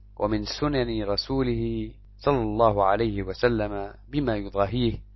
ch_00_arabic_tts_dataset_22.mp3